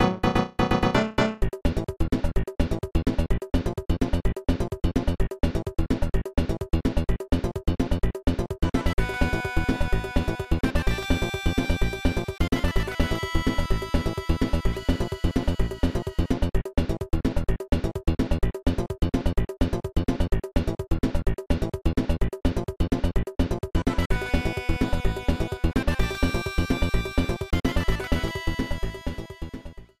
contribs)Added fadeout